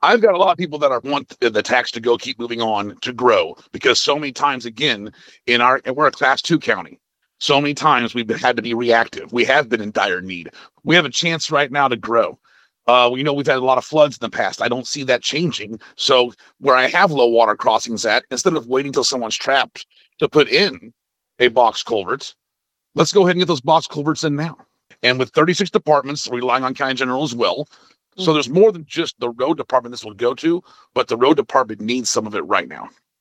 KTLO News spoke with Marion County Judge Jason Stumph who says currently the Sheriff’s department creates a shortfall of approximately $900,000 to $1.2 million annually for the county general fund.